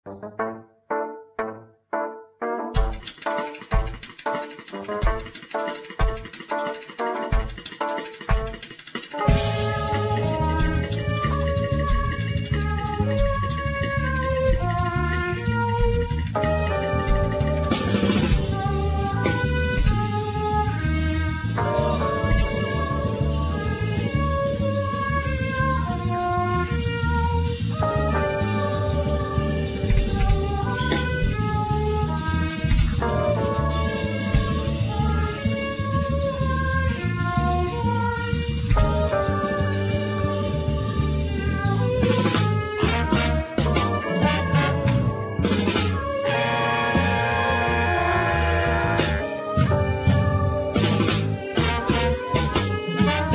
Guitar
Violin
Keyboards
Sax
Trumpet
Trombone